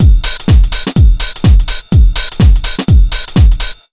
125BEAT2 4-L.wav